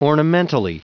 Prononciation du mot ornamentally en anglais (fichier audio)
Prononciation du mot : ornamentally